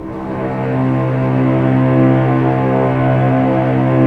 Index of /90_sSampleCDs/Roland LCDP08 Symphony Orchestra/STR_Vcs Bow FX/STR_Vcs Sul Pont